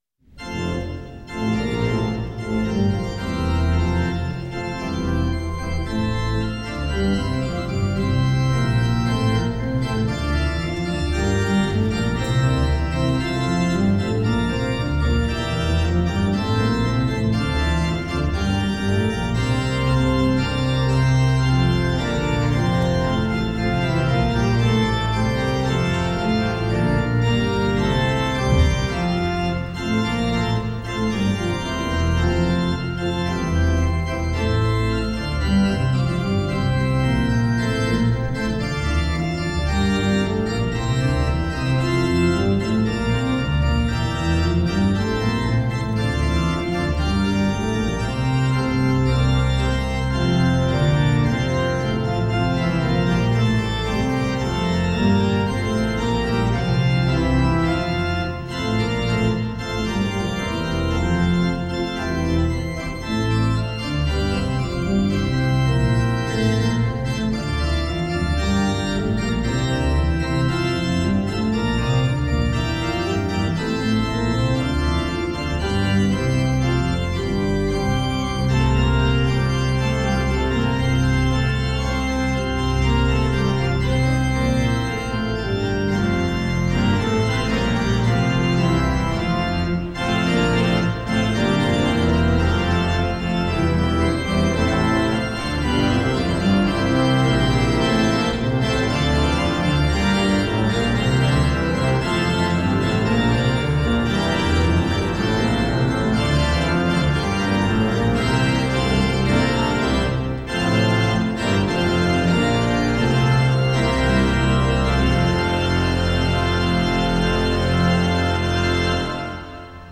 Hear the Bible Study from St. Paul's Lutheran Church in Des Peres, MO, from December 21, 2025.